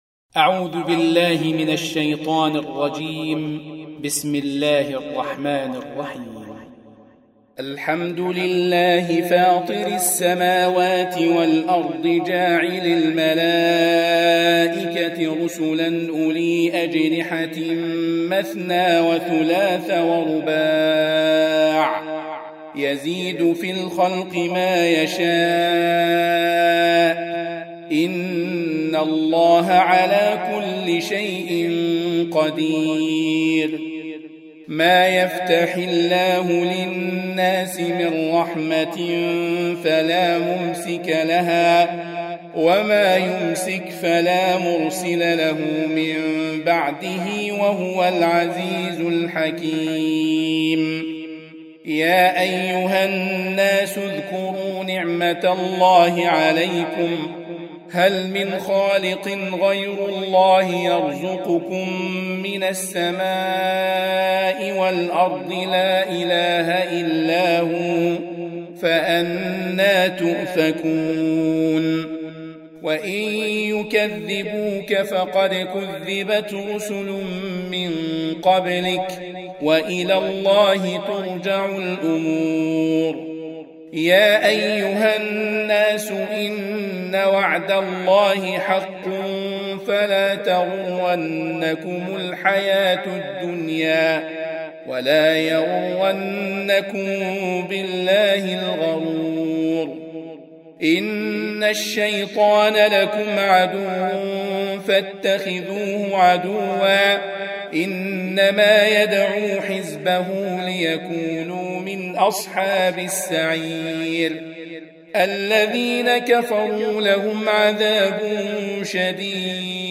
Surah Sequence تتابع السورة Download Surah حمّل السورة Reciting Murattalah Audio for 35. Surah F�tir or Al�Mal�'ikah سورة فاطر N.B *Surah Includes Al-Basmalah Reciters Sequents تتابع التلاوات Reciters Repeats تكرار التلاوات